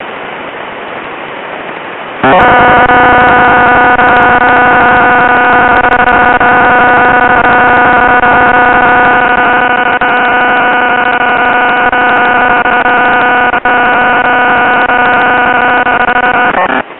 модуляция флешки 2